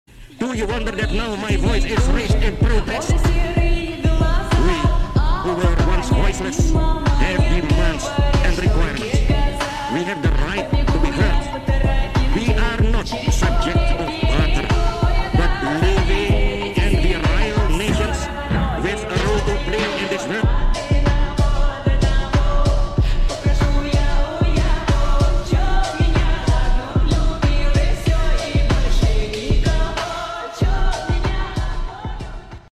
Momen pidato presiden Soekarno di